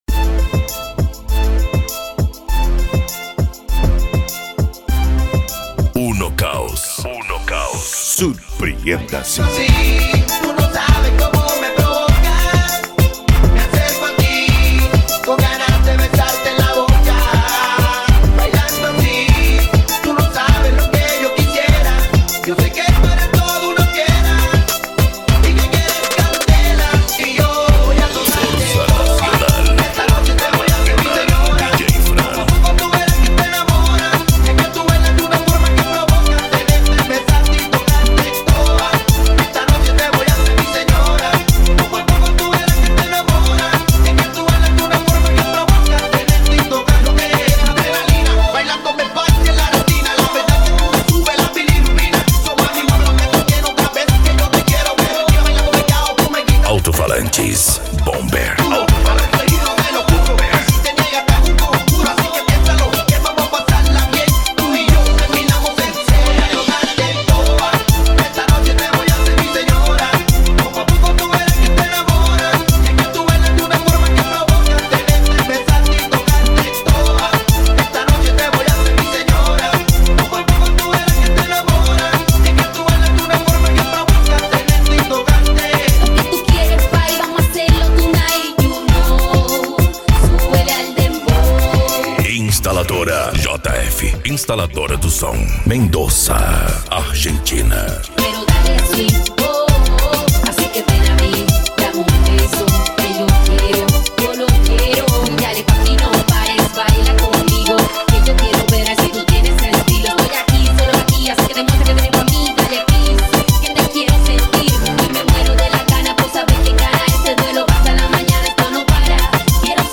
Bass
Musica Electronica
Remix